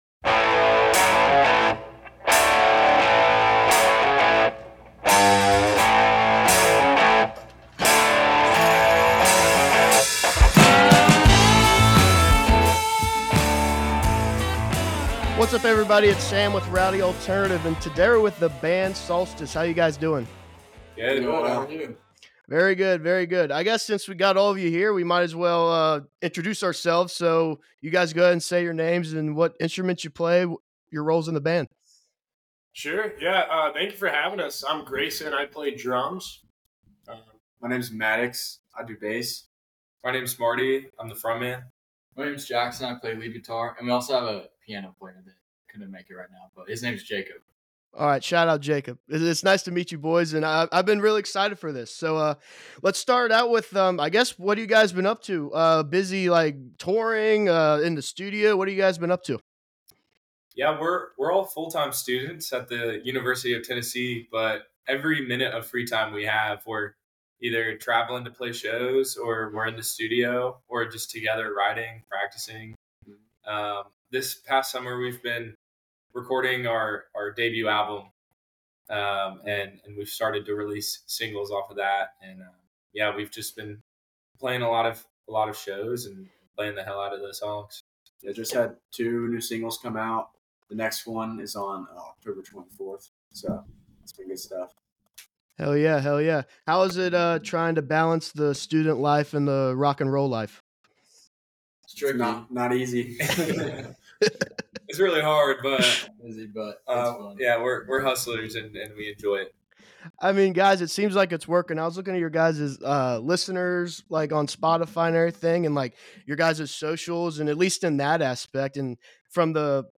Today we chat with The Band Solstice. We talk about the indie rock scene, balancing music and normal life and how they experiment in the studio.